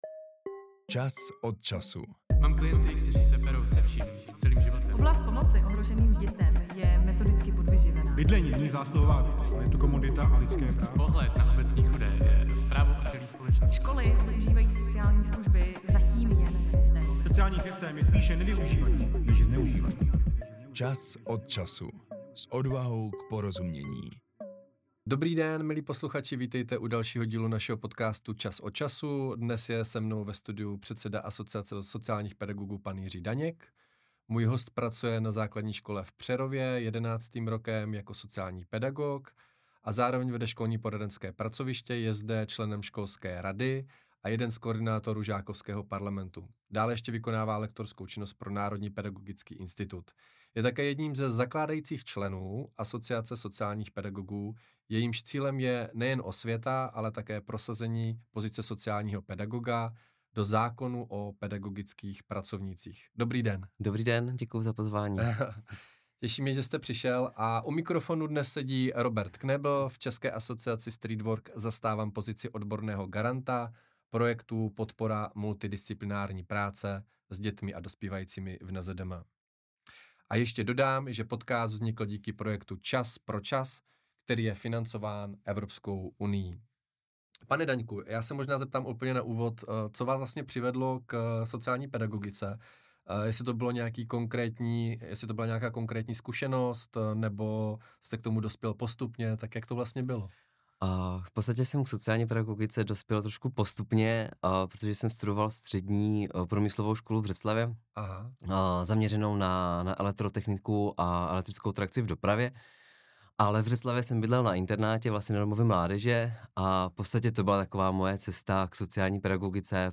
Nový díl se dále zaměří na mezioborovou spolupráci či etický kodex sociálních pedagogů. Rozhovor